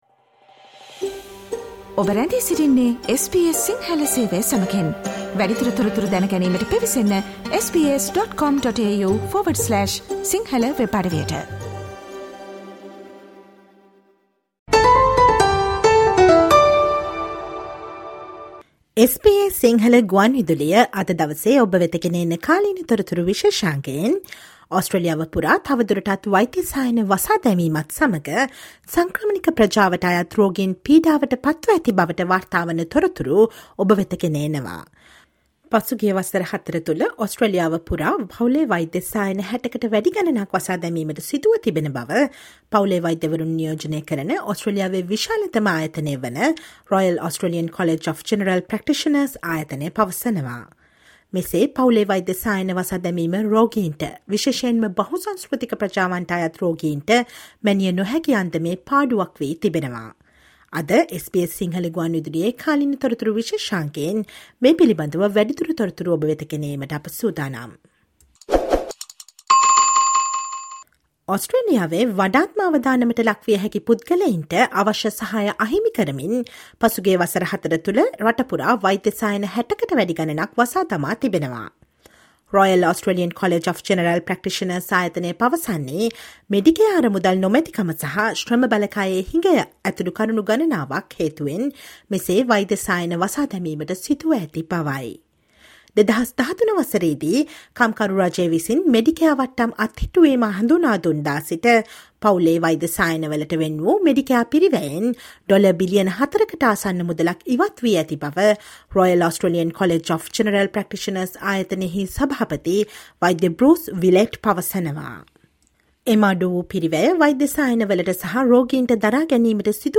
Listen to the SBS Sinhala radio current affair feature on the latest updates of the government response to GP clinic closure across the country.